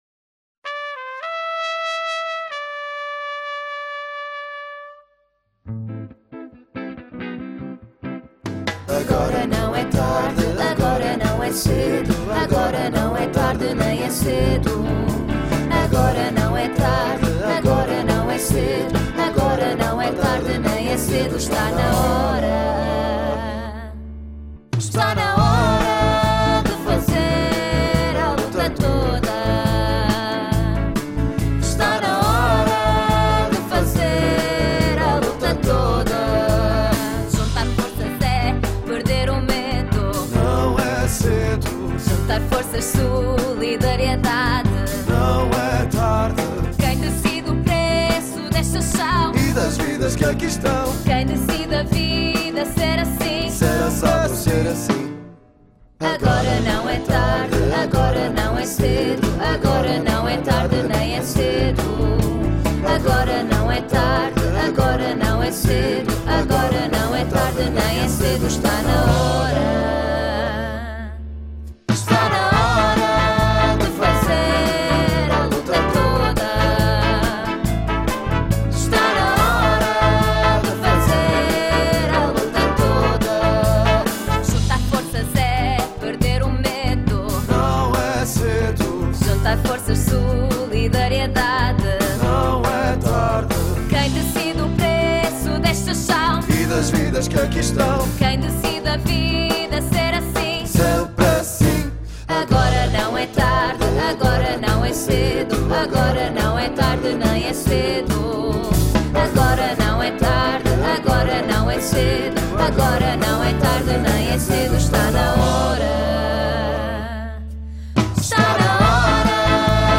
Cantada